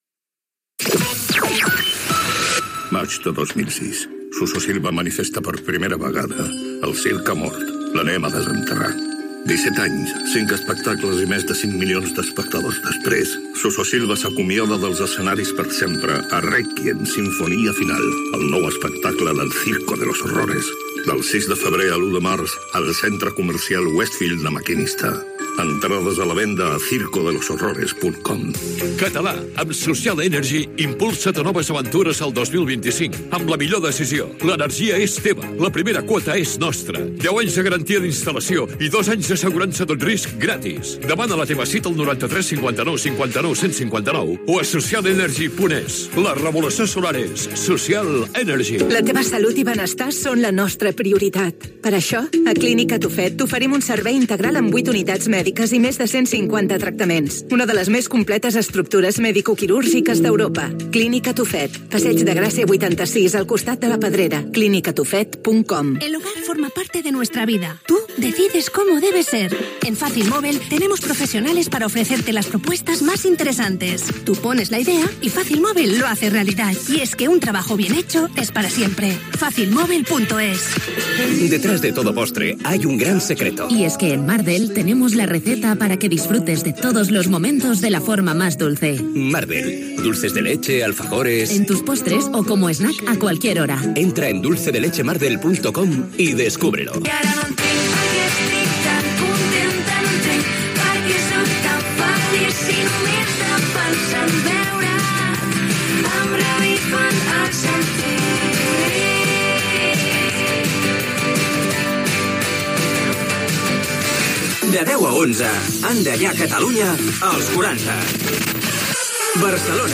Publicitat, tema musical, indicatiu de la ràdio amb esment a l'emissora de Ripollès Garrotxa.
Musical
FM